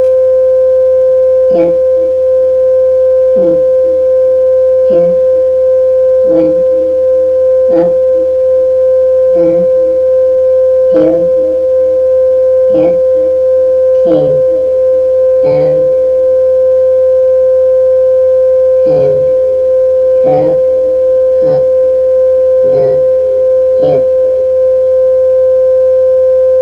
It started with an ambient amplification of sounds in the air but at the threshold of this sound being created, and which came as a surprise to me, was a tone generated by the electronics itself and hence to see if this set up will modulate to the spoken word.
modulating a tone
In the example above the tone was integrated within the amplification and the recording and hence is somewhat closer to the example at the whitechapel gallery – rather than the tone being created separately. Â There is modulation of the tone to the spoken word.